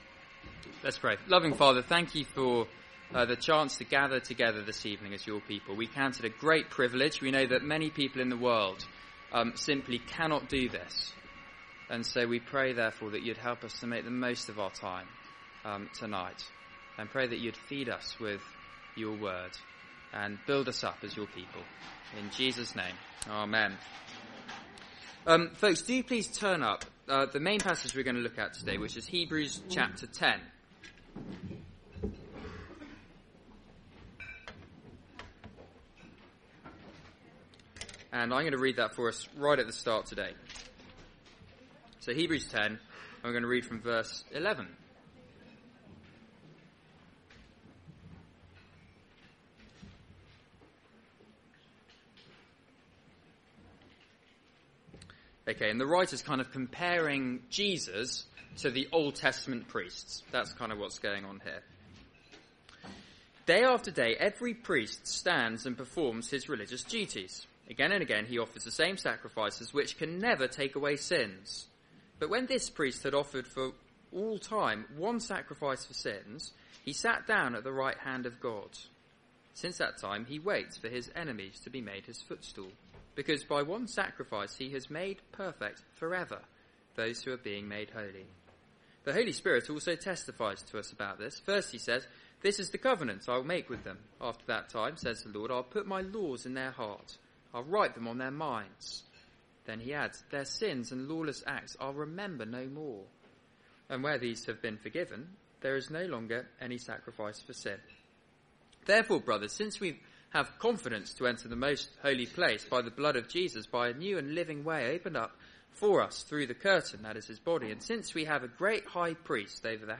Media for Seminar on Thu 04th Dec 2014 19:30 Speaker